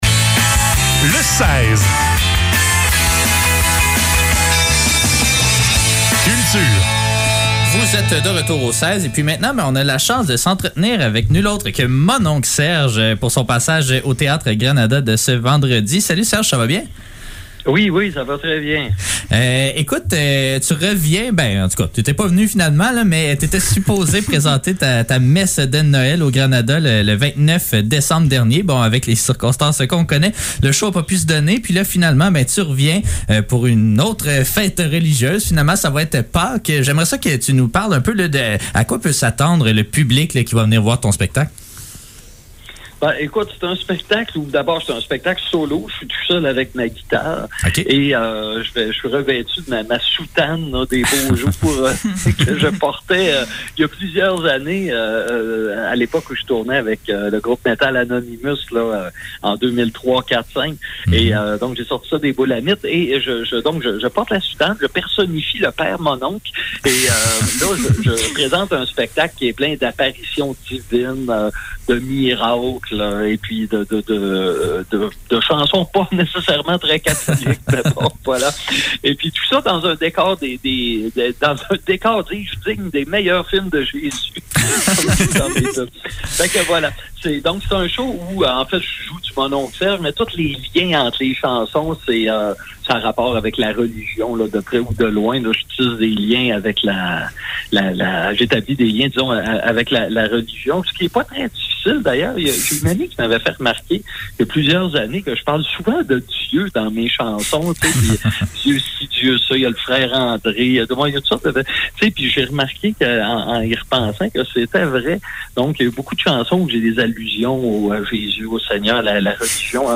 Le seize - Entrevue avec Mononc' Serge - 13 avril 2022